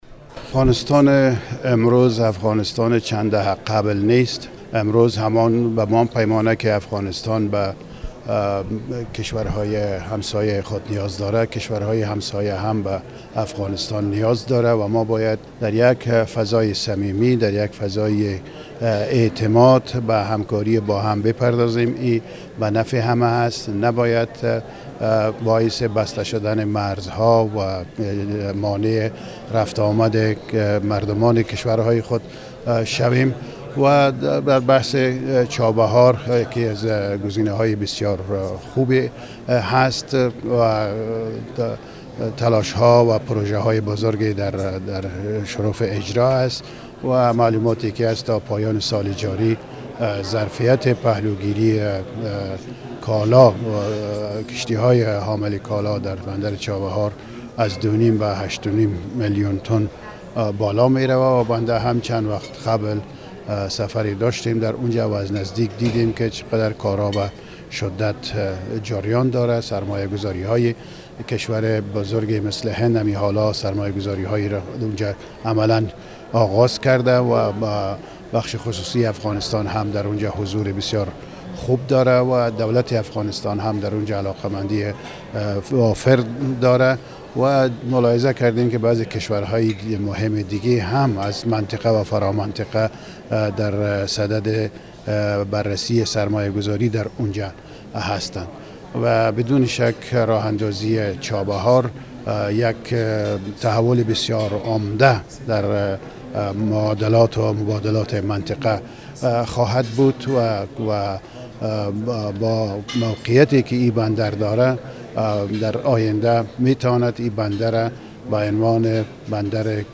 نصیر احمد نور در گفت و گو با خبرنگار رادیو دری با اشاره به اقدامات پاکستان مبنی بر مسدود کردن مرزهایش با افغانستان و ایجاد مشکل برای تاجران افغان و رفت و آمد بین دو کشور اظهار داشت: بندر چابهار در ایران از گزینه های بسیار خوب برای تاجران افغان بوده و پروژه های خوبی هم در آن حال اجرا است.